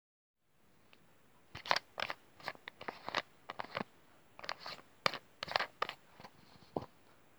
Het klinkt als stoepkrijten maar ik denk niet dat dat het is haha